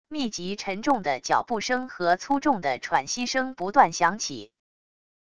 密集沉重的脚步声和粗重的喘息声不断响起wav音频